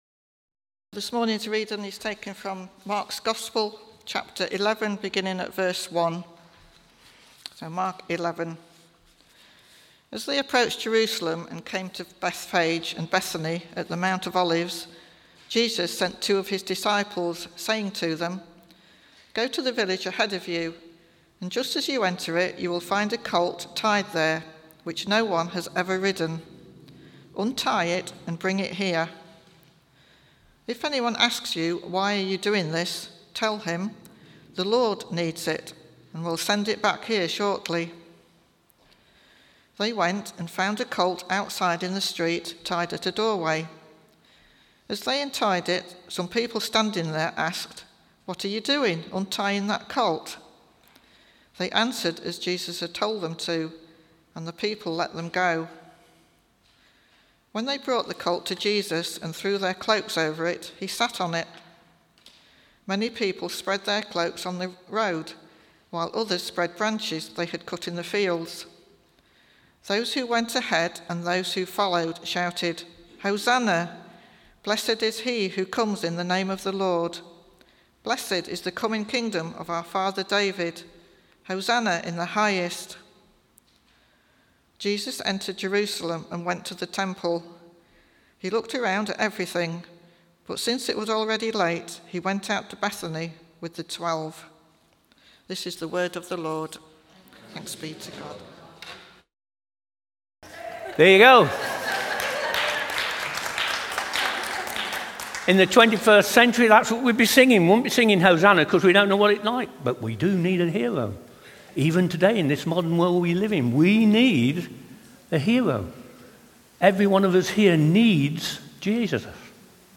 Eastgate Union Palm Sunday talk 2025